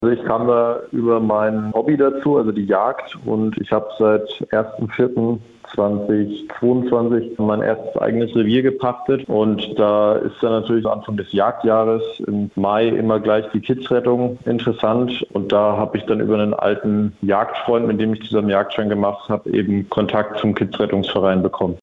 Was macht eigentlich ein Rehkitzretter? Interview